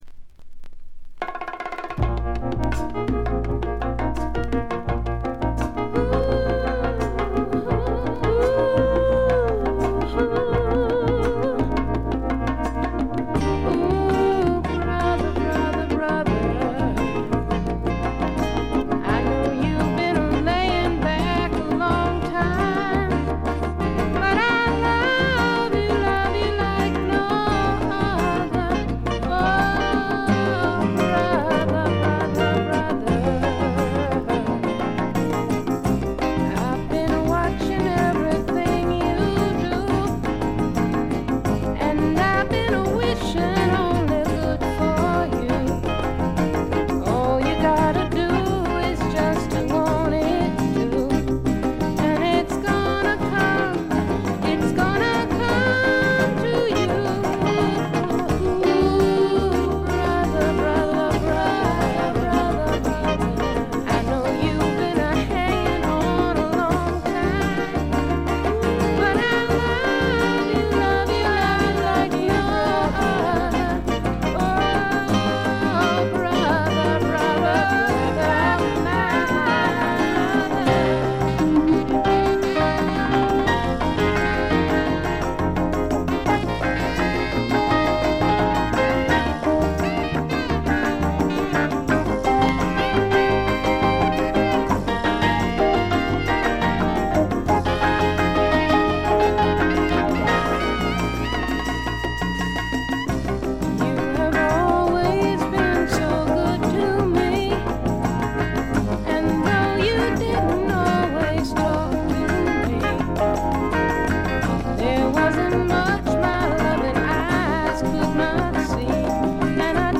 チリプチ少々（A1フェードアウトの消え際とか）。
初期仕様クアドラフォニック4チャンネル盤。
試聴曲は現品からの取り込み音源です。